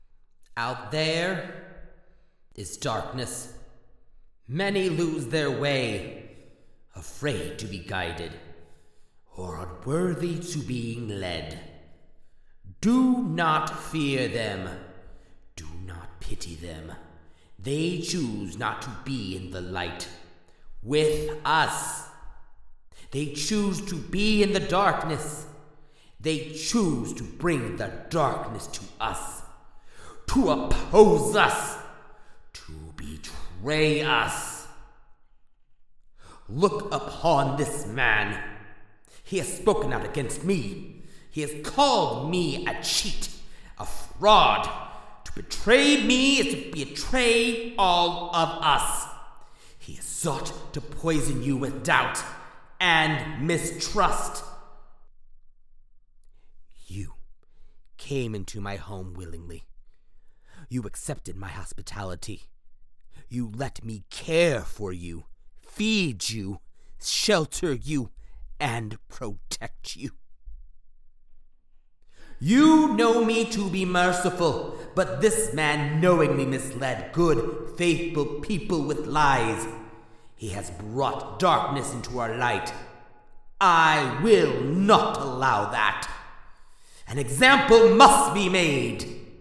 An Example Made Speech Video Game/Anime